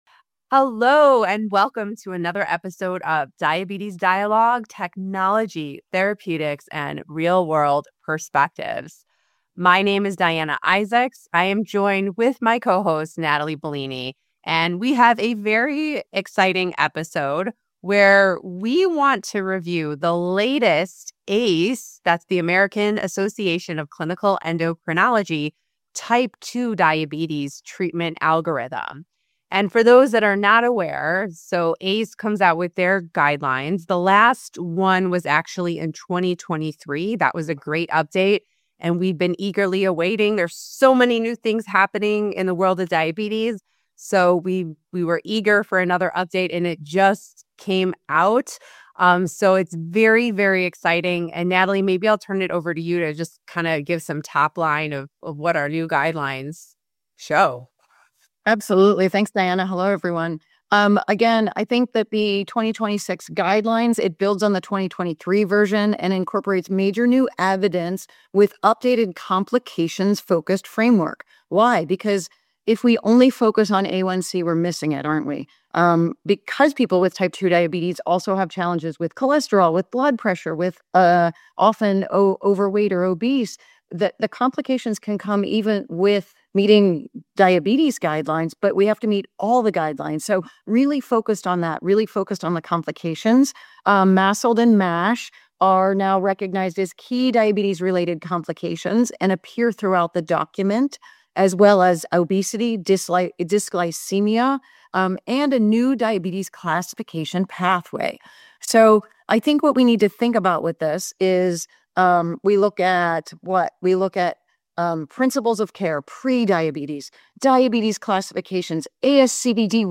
The discussion outlines the guideline’s structure, including its 10 guiding principles, which reinforce lifestyle intervention as foundational, promote individualized glycemic targets (with a preference for A1c ≤6.5% when safely achievable), and strongly encourage early use of continuous glucose monitoring (CGM). The hosts underscore the emphasis on avoiding therapeutic inertia, minimizing hypoglycemia risk, and managing cardiometabolic comorbidities alongside glycemia as part of routine care.